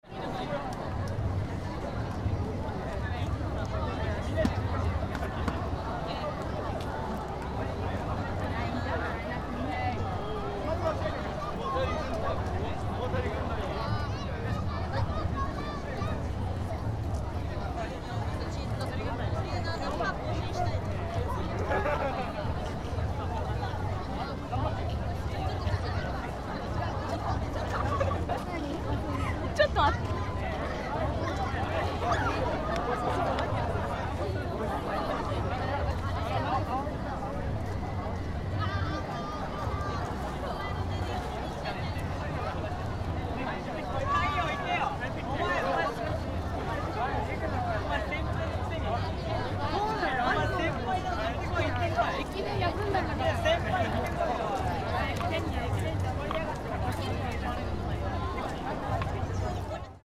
On the day of the Waraji (Japanese sandal) Festival, food and drink stalls were installed in Machinaka Square, and many people gathered there.